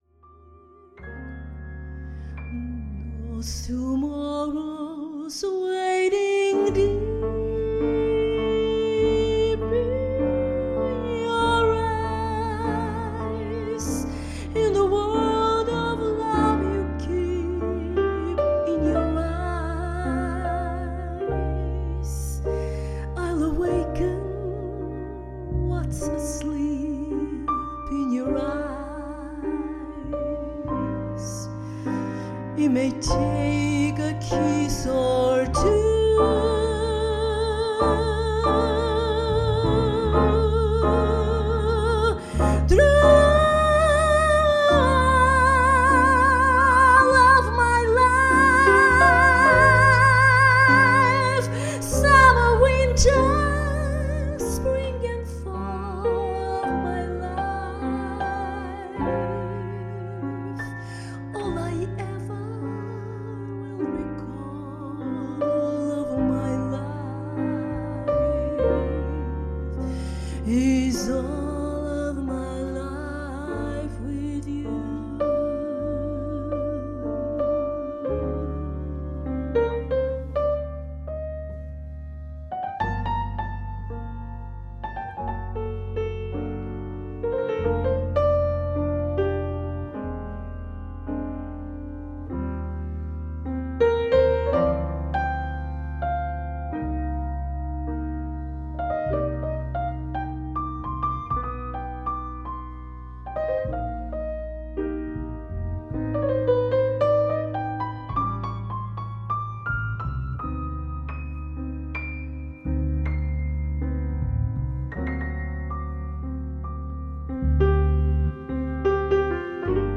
To illustrate, here are excerpts from two jazz tunes I've recorded during practice with my two friends musicians - a singer and a piano player. On these recordings I'm playing my Stagg EUB through a small Markbass Micromark 801 bass amp. No equalizers, reverbs or any other electronic gadgets between the Stagg output and the amp. The sound was recorded with a Zoom Q2n handy video recorder located about 2m (6 feet) from the amp's speaker.
Slow tempo tune - Stagg EUB sound sample -